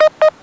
- Two beeps heard after initial audio monitor period ends.
614hz.wav